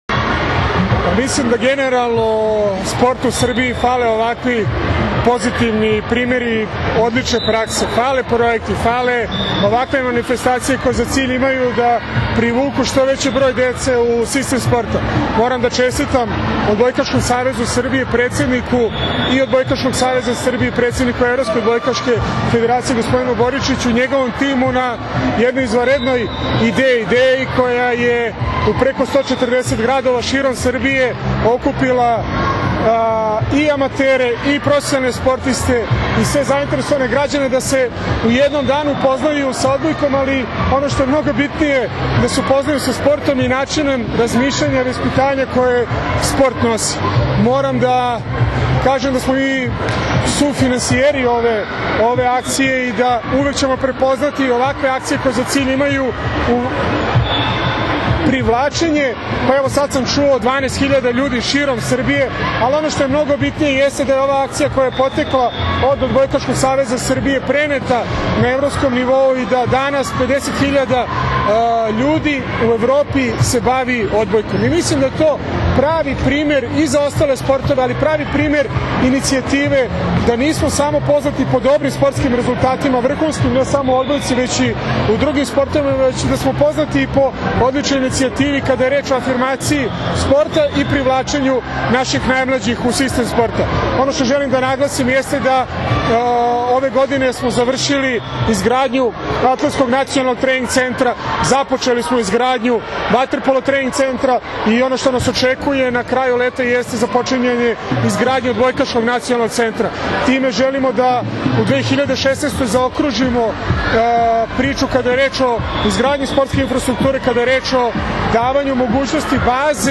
IZJAVA VAJE UDOVIČIĆA